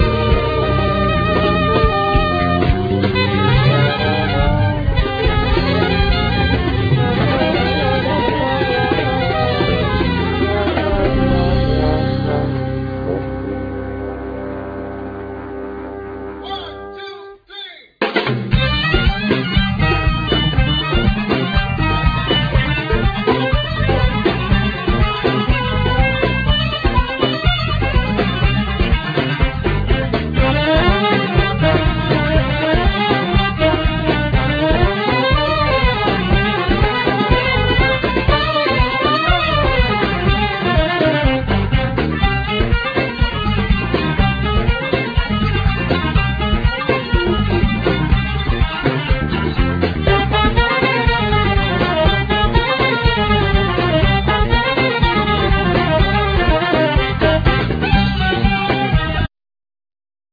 Bass,Guitar
Percussion
Vocals
Trumpet
Trombone
Trap drums
Guitar,Effects
Alto and tenor saxophone,Flute
Oud
Dharabuka
Clarinet
Violin
Kanoun
Nay
Saz